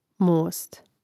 mȏst most